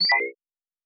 pgs/Assets/Audio/Sci-Fi Sounds/Interface/Error 15.wav at 7452e70b8c5ad2f7daae623e1a952eb18c9caab4
Error 15.wav